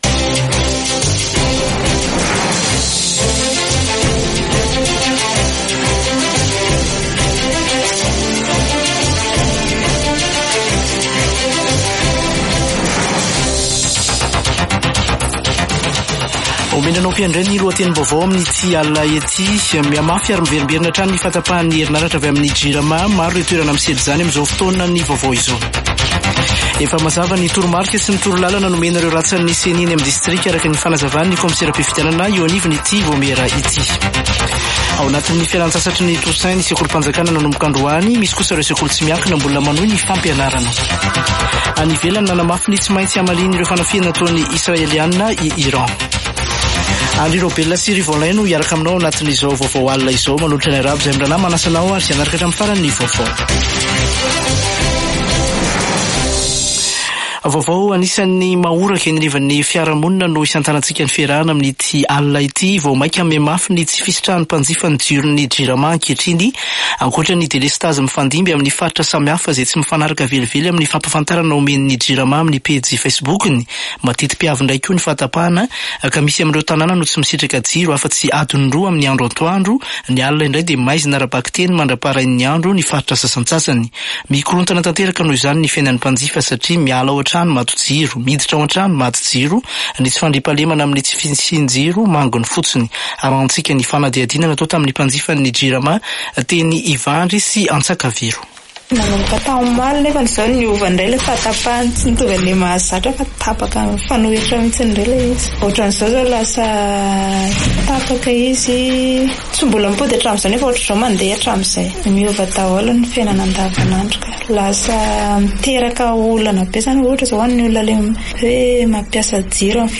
[Vaovao hariva] Alatsinainy 28 ôktôbra 2024